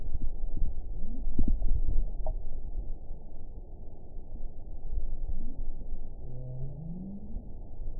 event 921288 date 05/06/24 time 23:55:28 GMT (1 year, 1 month ago) score 8.22 location TSS-AB05 detected by nrw target species NRW annotations +NRW Spectrogram: Frequency (kHz) vs. Time (s) audio not available .wav